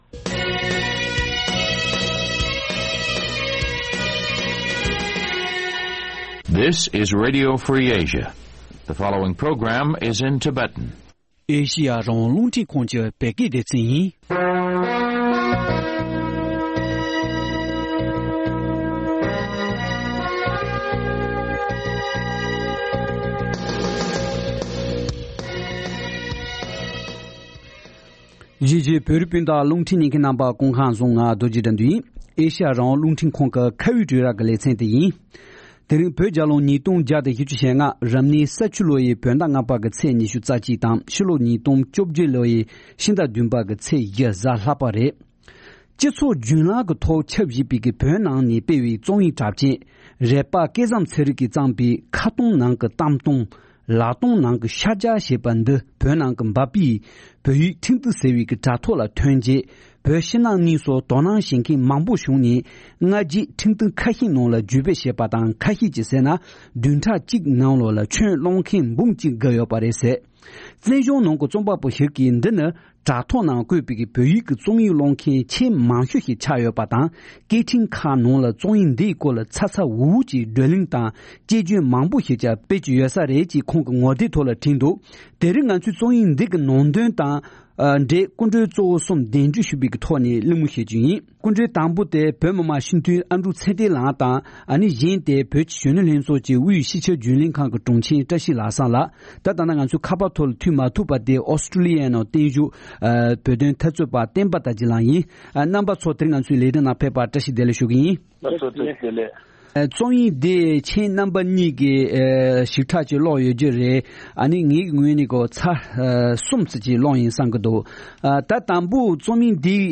ཁ་སྟོང་ནང་གི་གཏམ་སྟོང་། ལག་སྟོང་ནང་གི་ཕྱག་རྒྱ་ ཞེས་པའི་རྩོམ་ཡིག་ཐད་གླེང་མོལ།